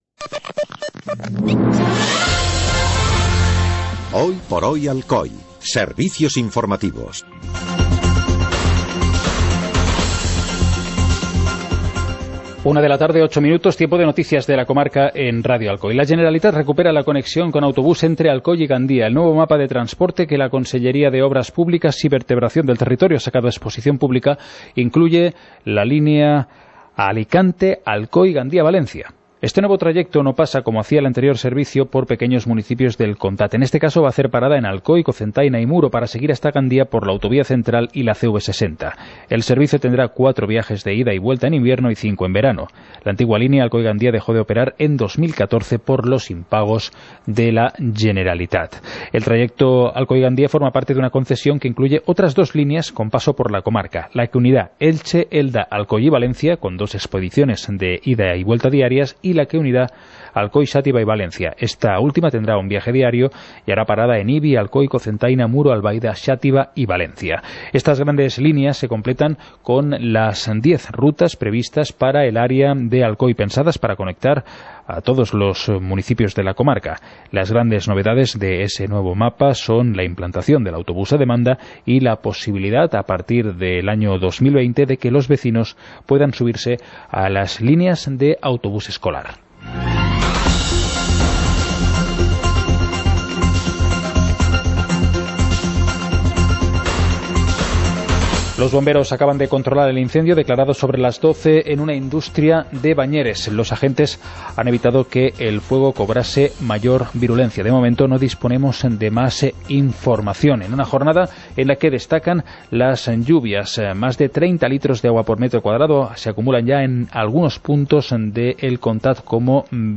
Informativo comarcal - jueves, 16 de agosto de 2018